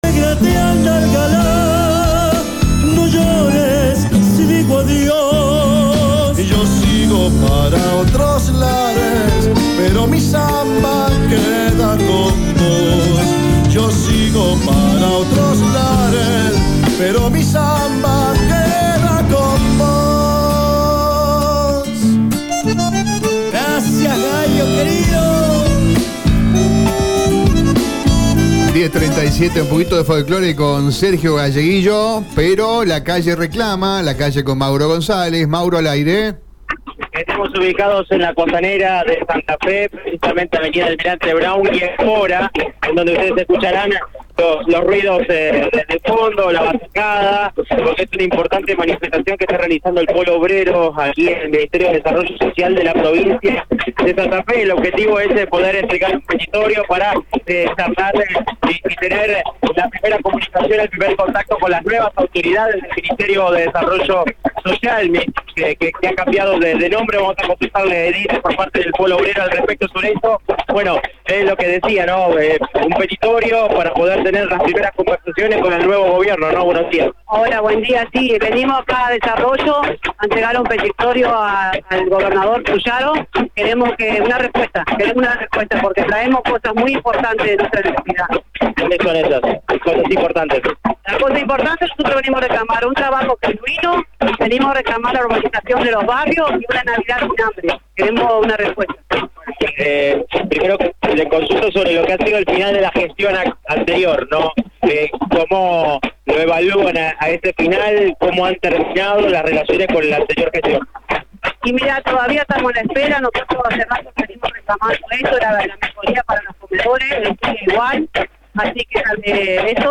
El Polo Obrero y organizaciones barriales se movilizaron este martes hasta la sede del Ministerio de Desarrollo Social de Santa Fe, ubicado en Avenida Almirante Brown y Espora, sobre la Costanera Oeste.